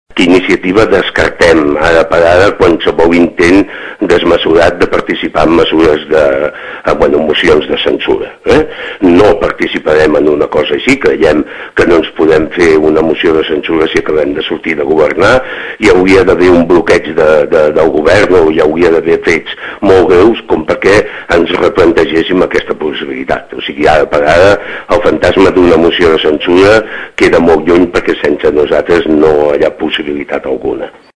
en una entrevista als serveis informatius d’aquesta emissora